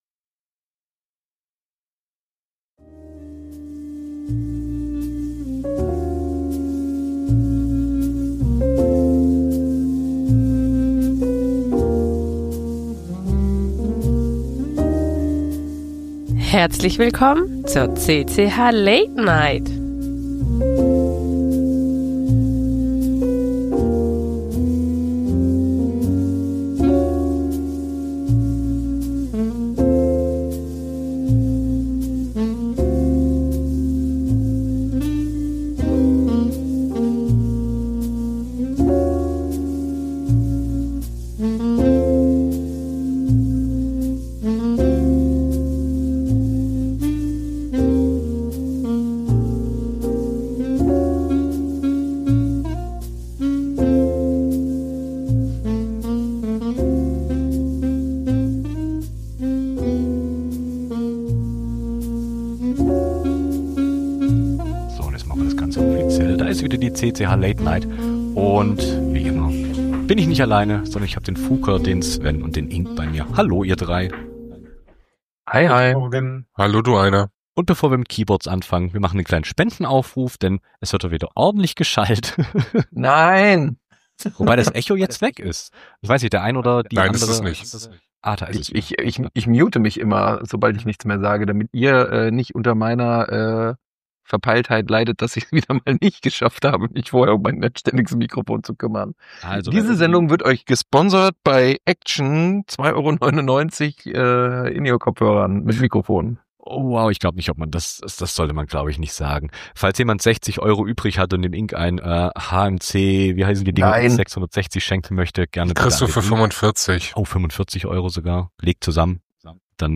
Die Recordings der CCH! Late Night vom 14.05.2025
Die CCH! Late Night! ist eure monatliche Live-Keyboard-Late-Night-Show.